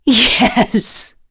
Seria-Vox_Happy4.wav